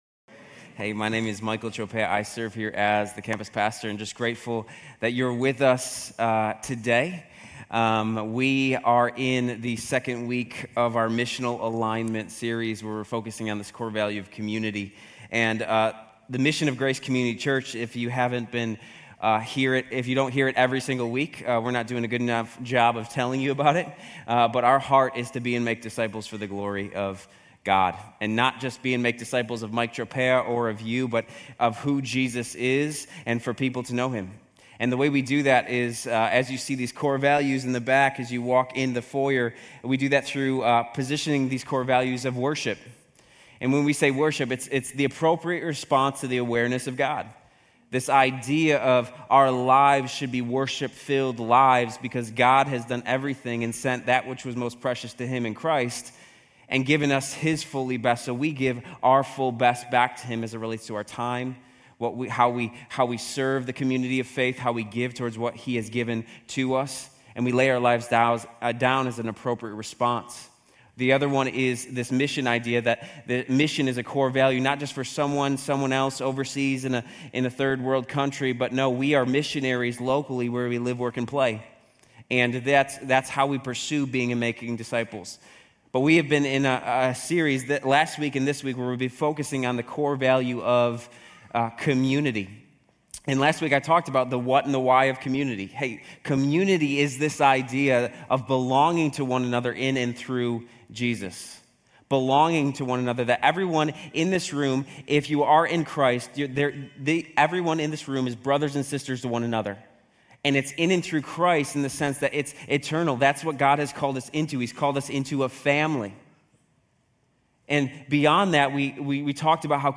Grace Community Church University Blvd Campus Sermons 8_11 University Blvd Campus Aug 11 2024 | 00:34:37 1x 00:00 / 00:34:37 Subscribe Share RSS Feed Share Link Embed